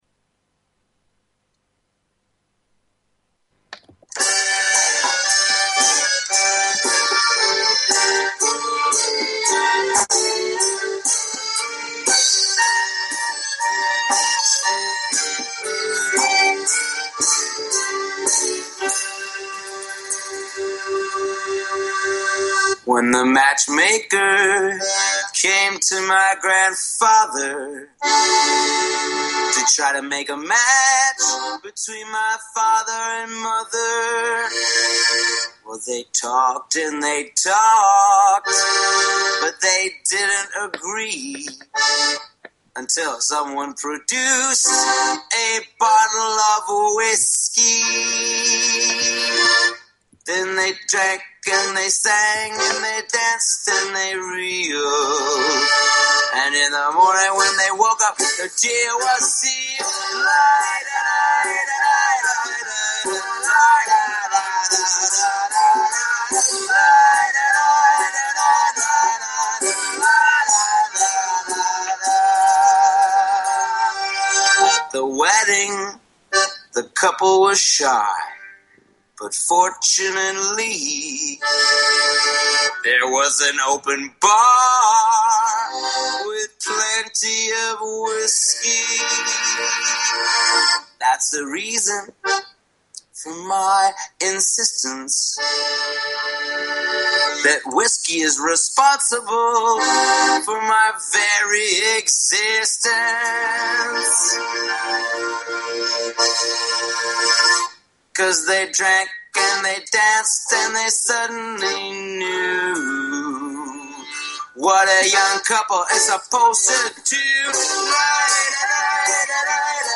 Talk Show Episode, Audio Podcast, New_Yiddish_Rep_Radio_Hour and Courtesy of BBS Radio on , show guests , about , categorized as
Talk radio in Yiddish, in English, sometimes a mix of both, always informative and entertaining. NYR Radio hour will bring you interviews with Yiddish artists, panel discussions, radio plays, comedy, pathos, and a bulletin board of events, as well as opinions and comments from listeners.